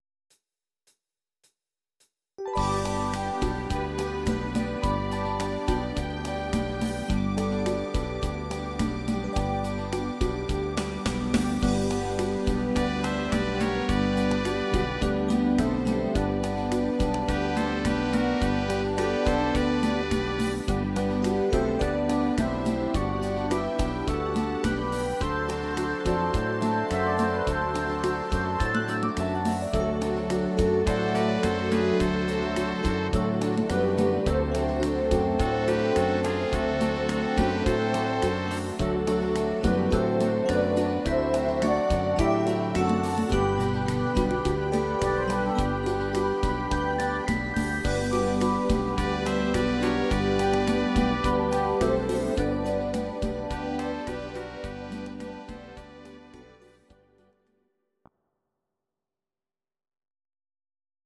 Audio Recordings based on Midi-files
Instrumental
instr. Orgel